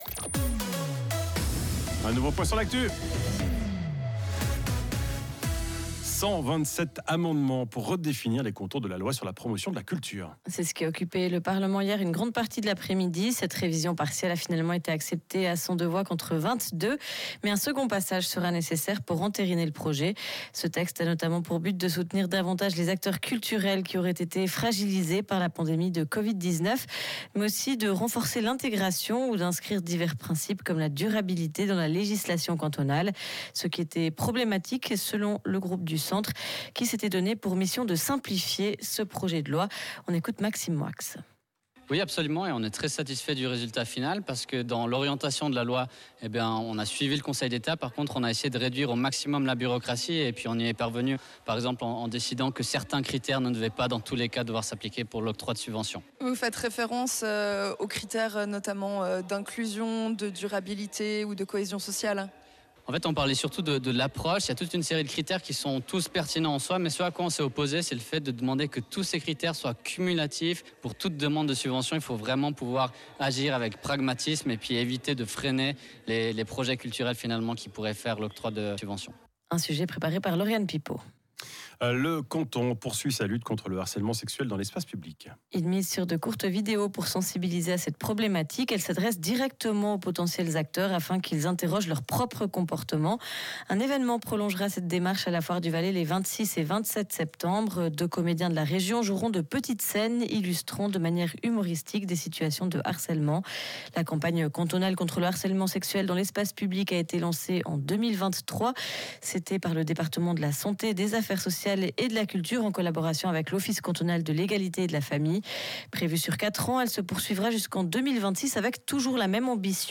12.09.2025 - Rhône FM : Interview sur la révision de la lois sur la promotion de la culture